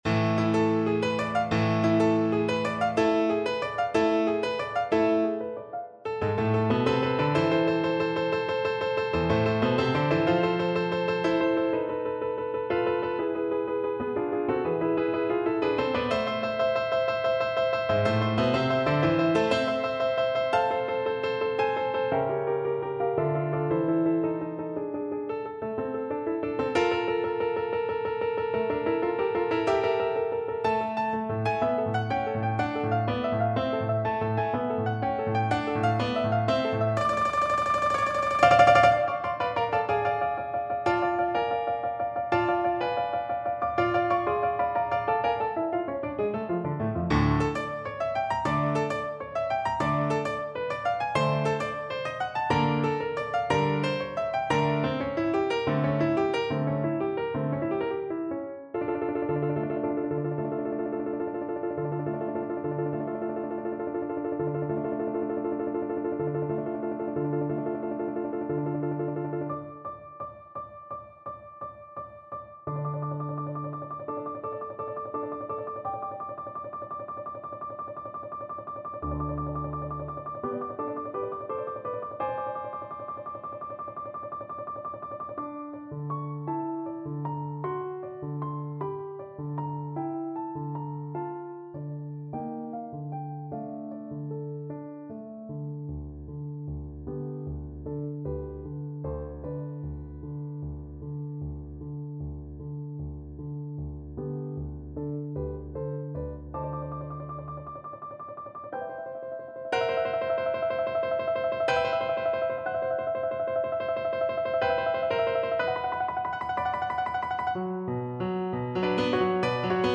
Schnell und wild = 185
Classical (View more Classical Flute Music)